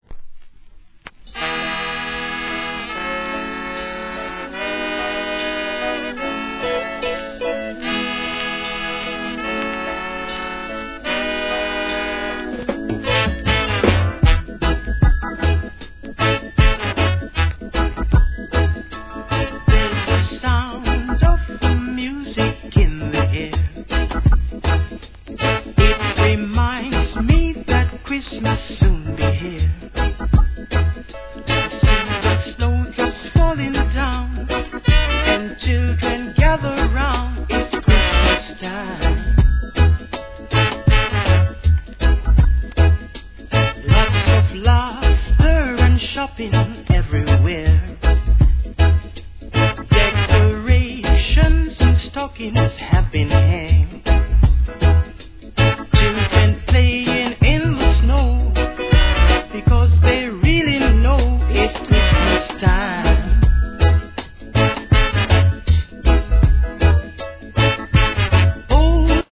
REGGAE
クリスマス・ソング